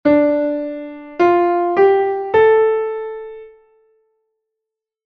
Nota de paso
RE-FA-SOL-LA